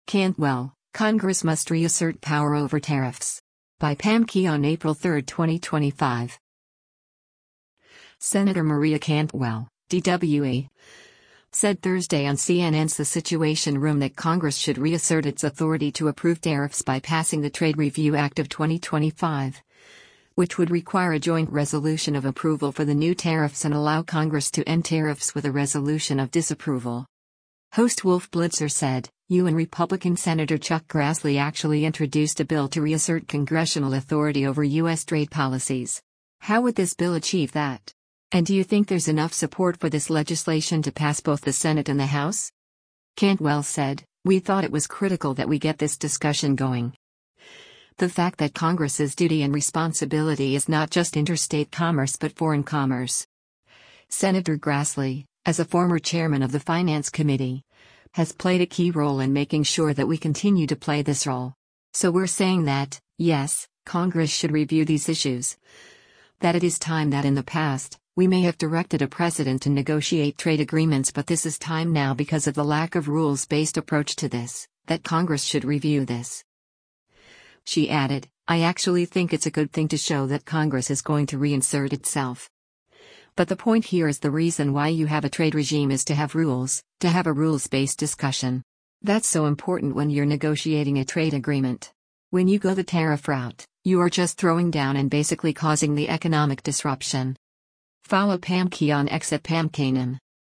Senator Maria Cantwell (D-WA) said Thursday on CNN’s “The Situation Room” that Congress should reassert its authority to approve tariffs by passing the Trade Review Act of 2025, which would require a joint resolution of approval for the new tariffs and allow Congress to end tariffs with a resolution of disapproval.